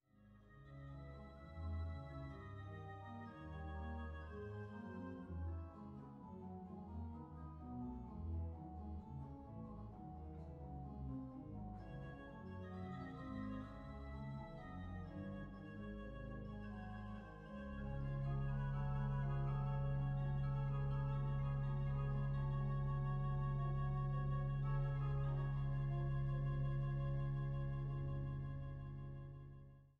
Schramm-Orgel der Stadtkirche St. Otto zu Wechselburg
à 2 Claviere è Pedale